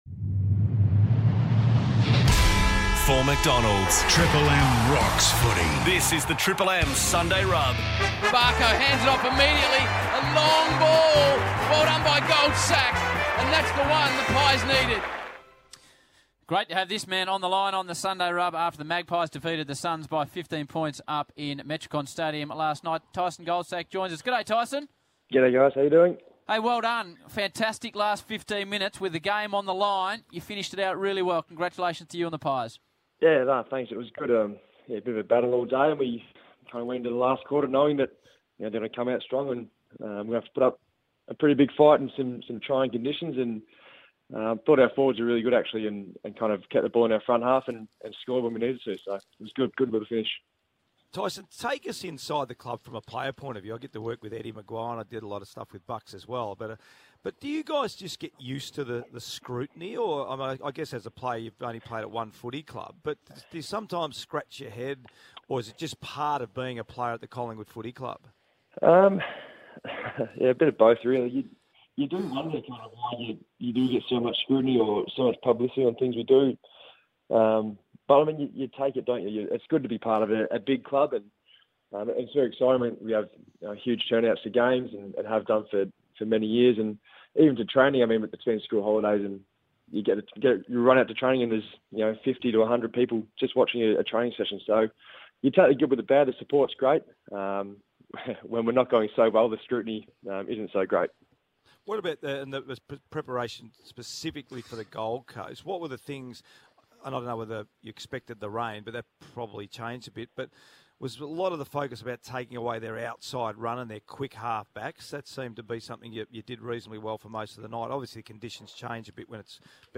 Listen to veteran defender Tyson Goldsack speak to Triple M's Sunday Rub after Collingwood's round 17 victory over the Gold Coast Suns.